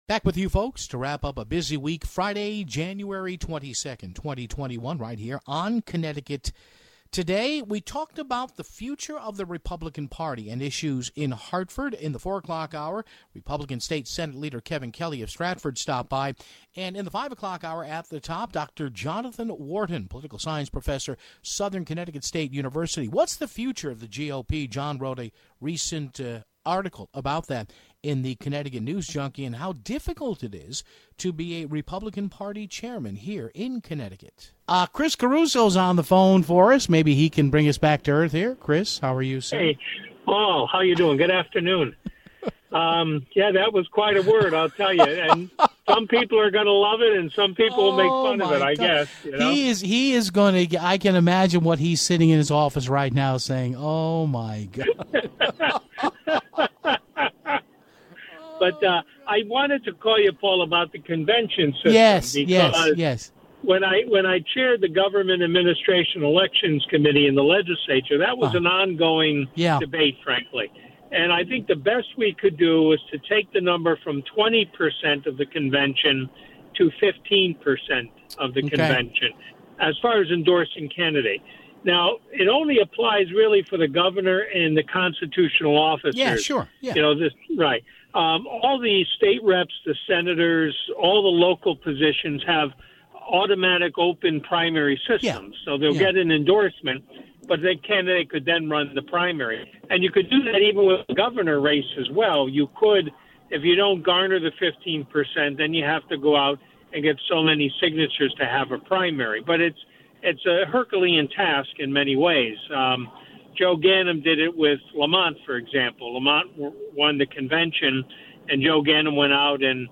First, former state representative Chris Caruso came on to talk about going straight to a primary vote, rather than holding a caucus (0:34). State Senator Kevin Kelly came on to talk about the Governor's extension of power during an emergency (6:08).